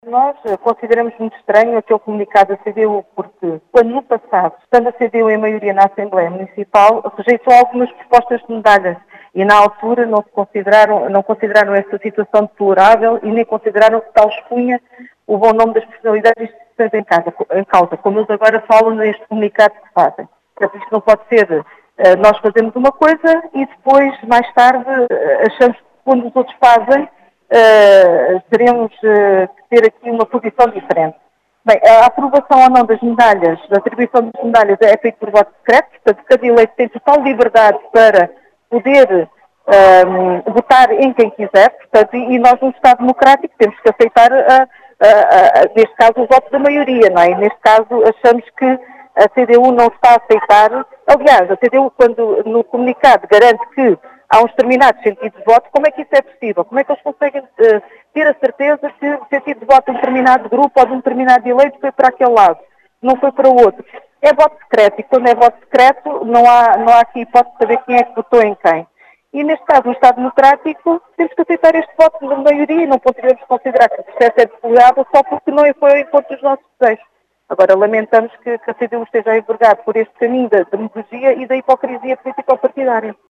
As explicações foram deixadas por Ana Horta, eleita do PS, na Assembleia Municipal de Beja, que acusa a CDU de “demagogia e hipocrisia”.